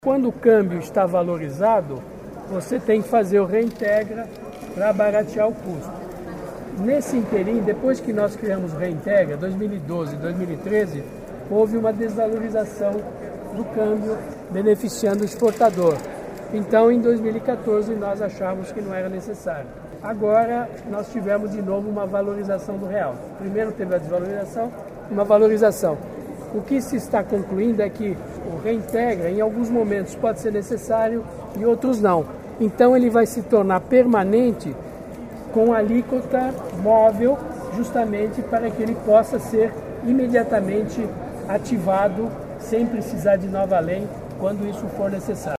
Mantega falou ainda sobre algumas mudanças do Reintegra.
GuidoMantegaReintegra.mp3